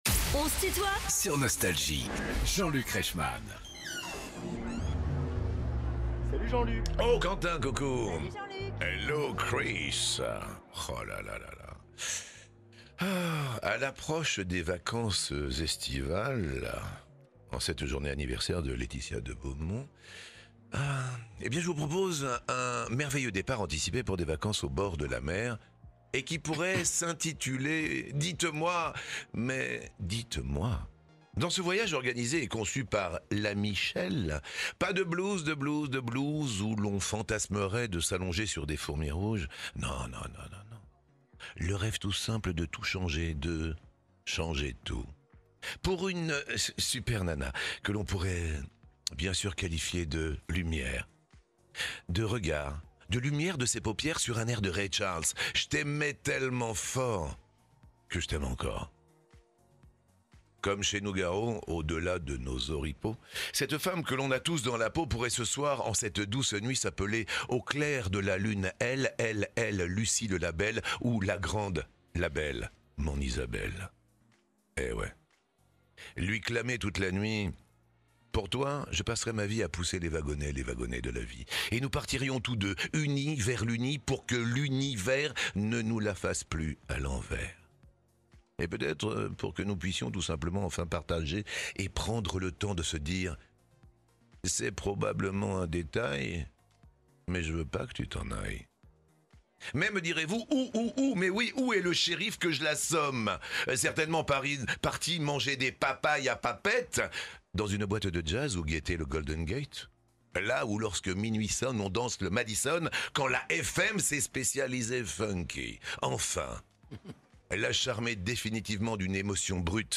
Michel Jonasz est l'invité de "On se tutoie ?..." avec Jean-Luc Reichmann (Partie 1) ~ Les interviews Podcast
Les plus grands artistes sont en interview sur Nostalgie.